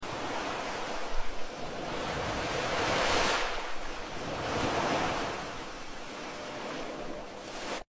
Satisfying ASMR videos of Dollar's sound effects free download